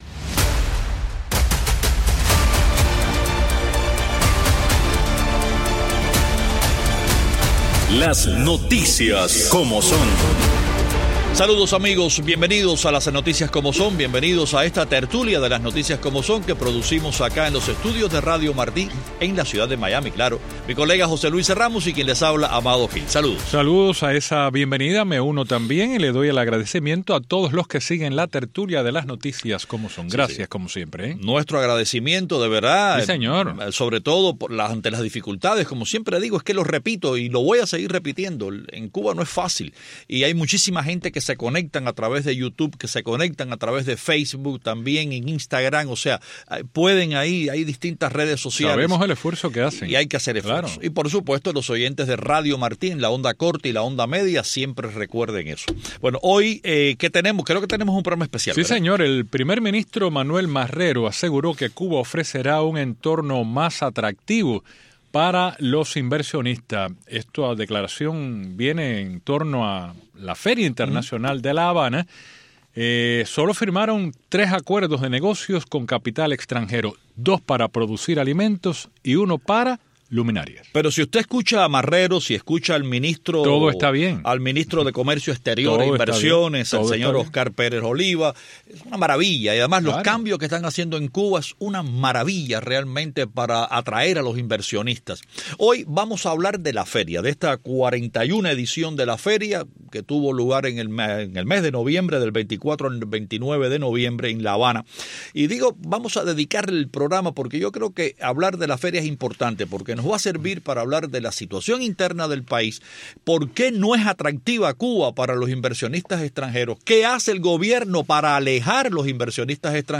en una tertulia especial